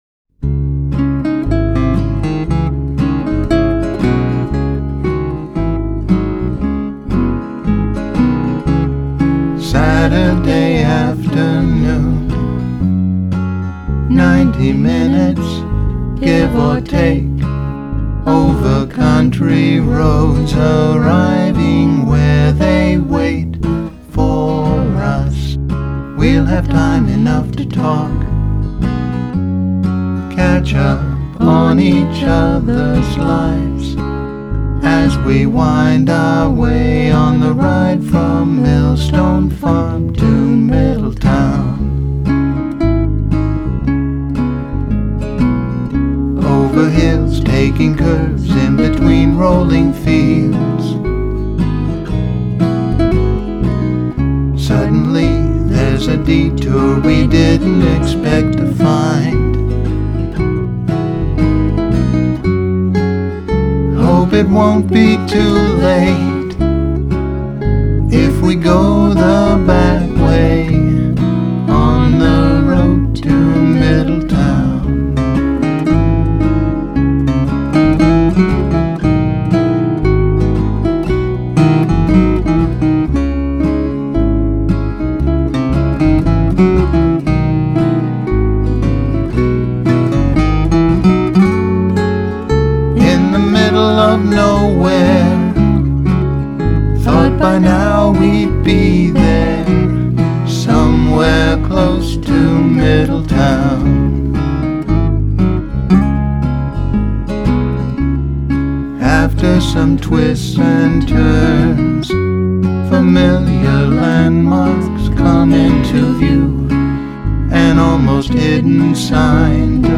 vocals and production
guitar